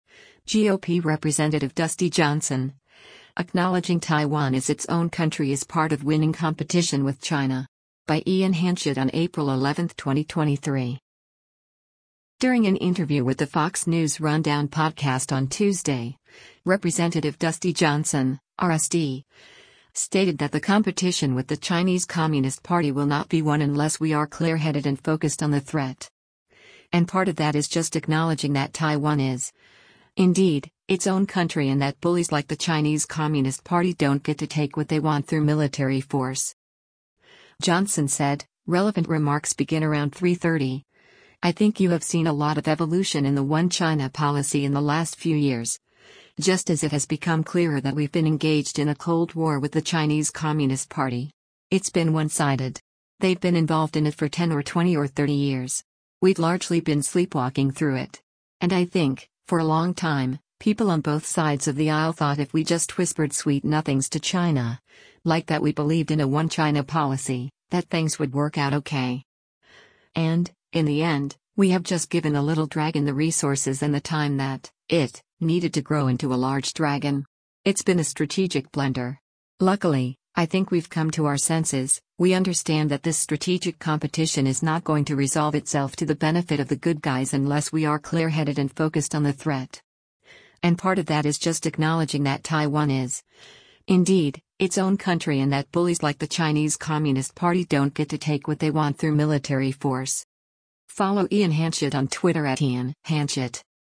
During an interview with the “Fox News Rundown” podcast on Tuesday, Rep. Dusty Johnson (R-SD) stated that the competition with the Chinese Communist Party will not be won “unless we are clear-headed and focused on the threat. And part of that is just acknowledging that Taiwan is, indeed, its own country and that bullies like the Chinese Communist Party don’t get to take what they want through military force.”